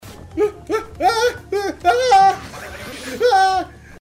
wa waaah waaaaaah
wa-waaaah-waaaaaaaah.mp3